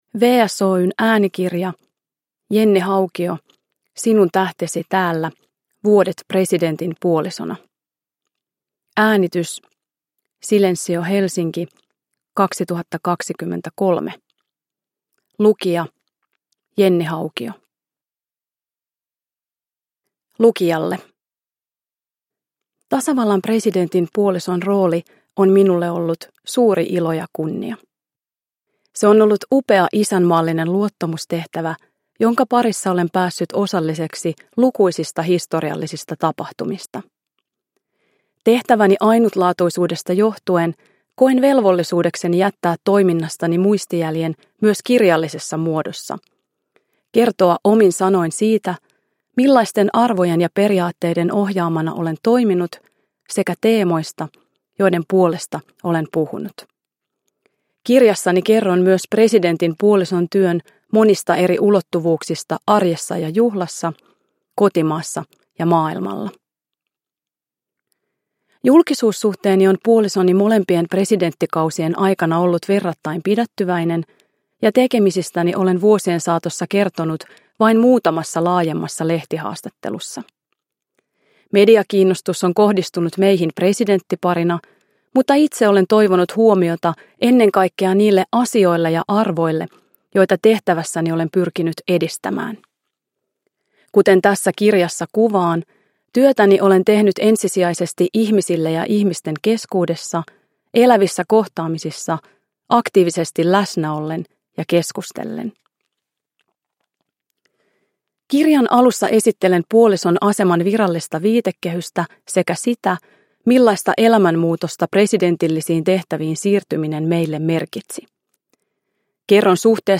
Sinun tähtesi täällä – Ljudbok – Laddas ner
Uppläsare: Jenni Haukio